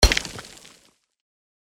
Mining_4.mp3